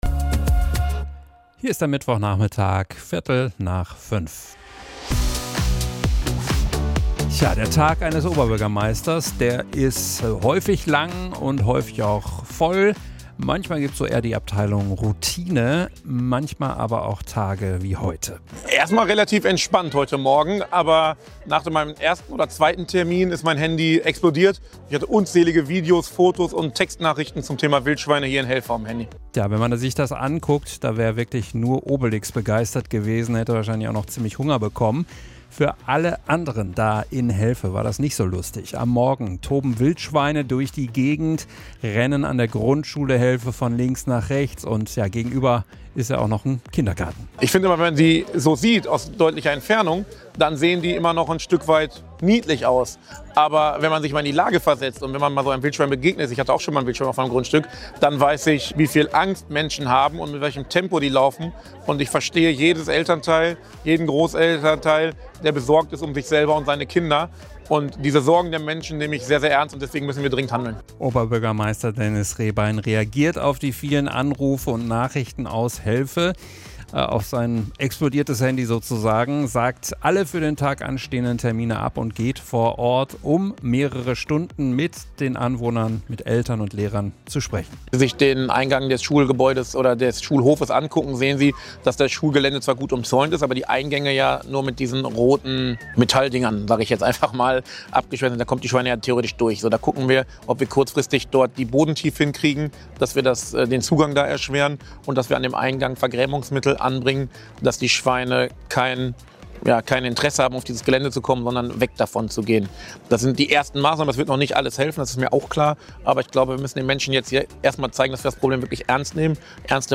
MITSCHNITT AUS DER SENDUNG